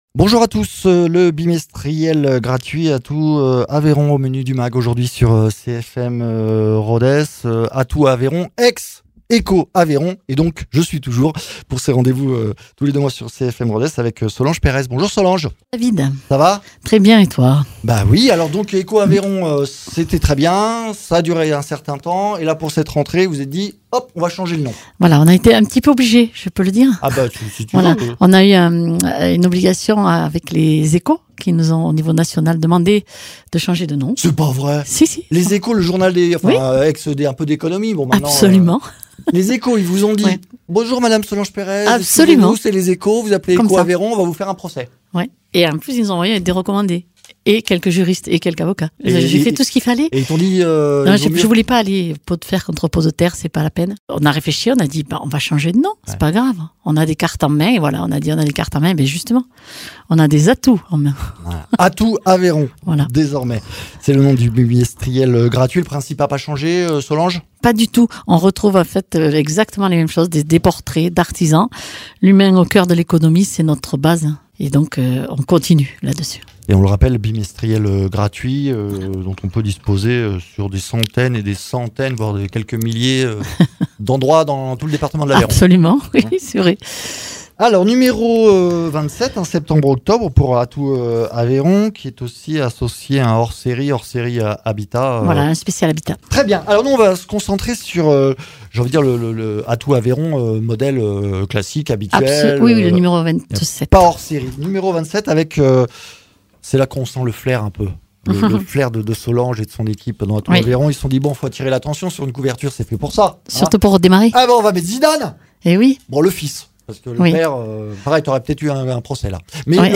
Le bimestriel aveyronnais gratuit change de nom pour cette rentrée mais pas d’identité, trois des personnalités à découvrir dans Atout Aveyron sont dans nos studios.